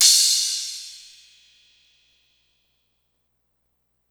crash 6 (mexiko dro).wav